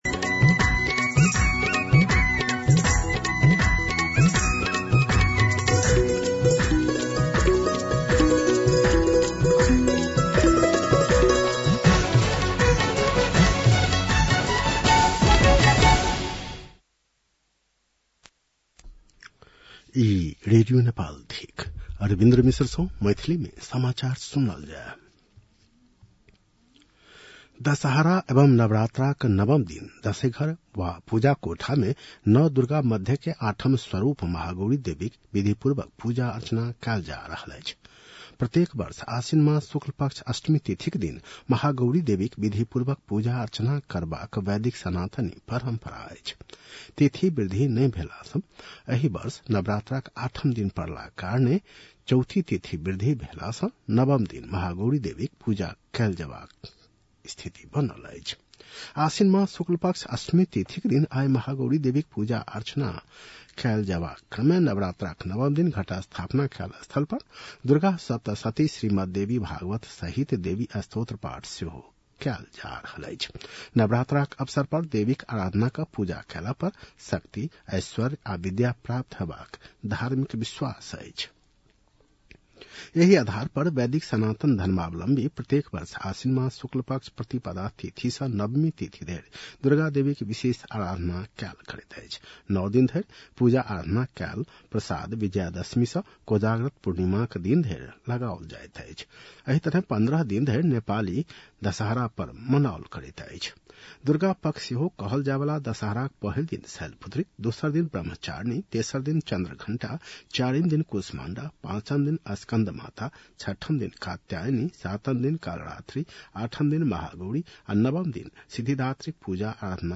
मैथिली भाषामा समाचार : १४ असोज , २०८२